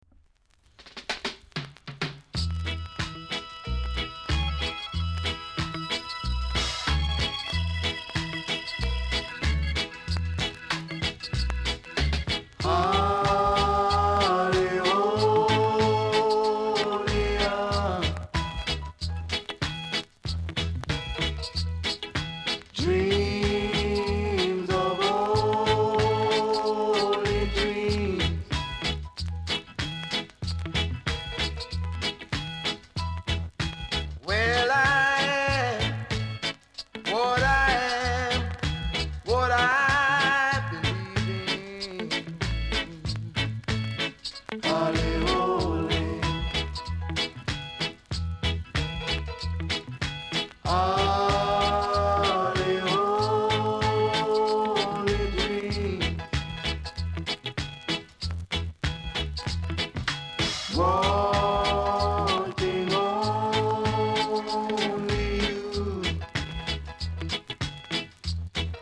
※全体的にチリノイズがあります。